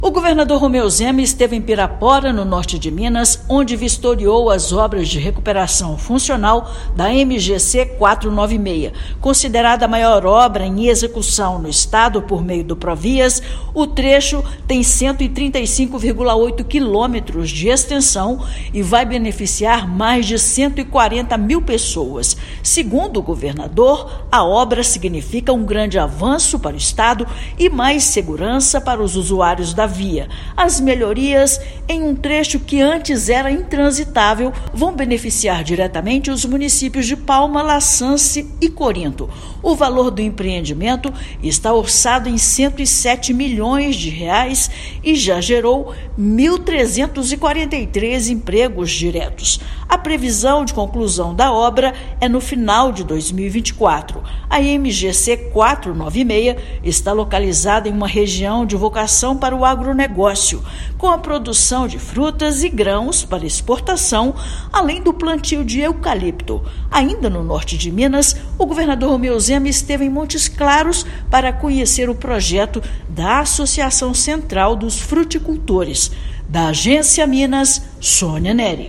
[RÁDIO] Governo de Minas vistoria MGC-496, maior obra rodoviária em execução no estado
São mais de 135,8 quilômetros para a recuperação funcional da rodovia que liga Corinto a Pirapora; mais de 140 mil pessoas serão beneficiadas. Ouça matéria de rádio.